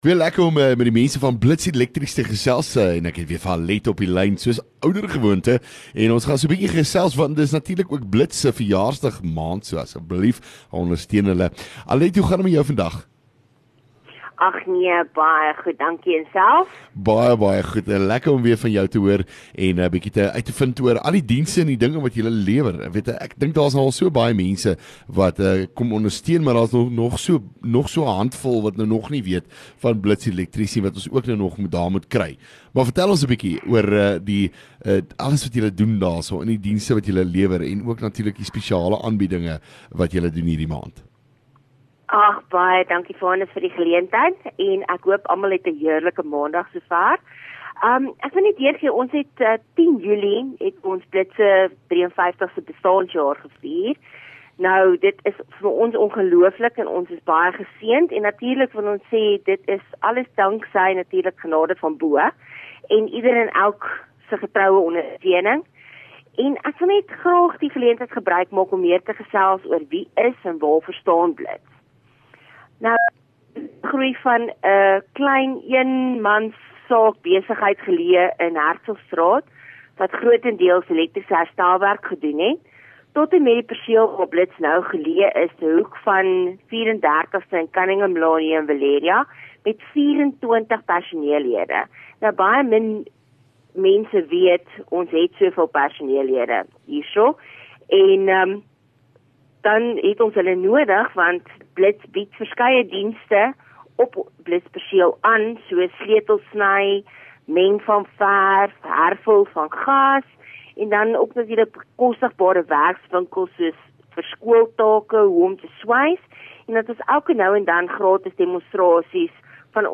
LEKKER FM | Onderhoude 17 Jul Blits Elektrisiëns